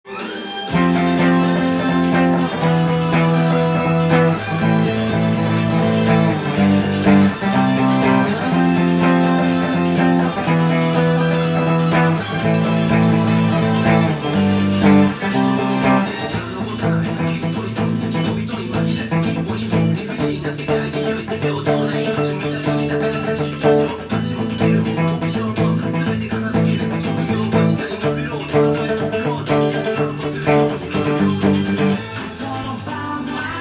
音源にあわせて譜面通り弾いてみました
全てパワーコードで弾けるように簡単にしているので
歌が始まるとブリッジミュートが登場しますが